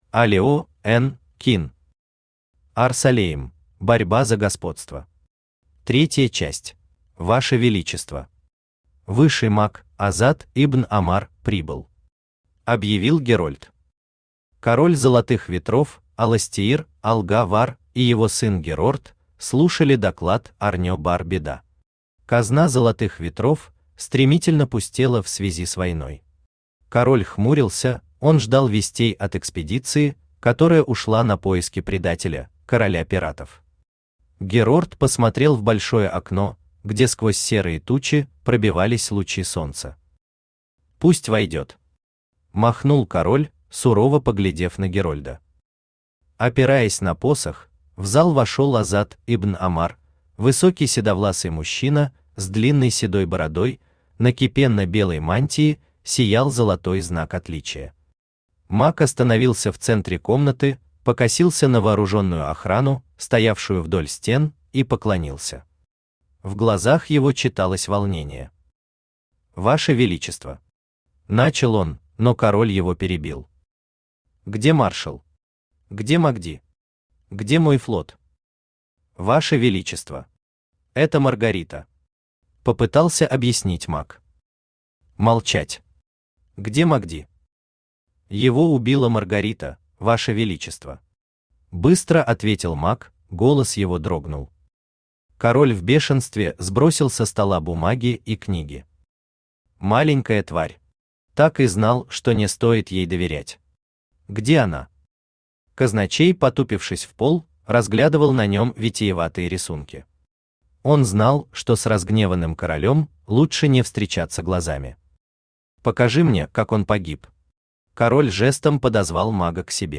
Аудиокнига Ар-Салейм. Борьба за господство. 3 часть | Библиотека аудиокниг
Борьба за господство. 3 часть Автор Олео Н.Кин Читает аудиокнигу Авточтец ЛитРес.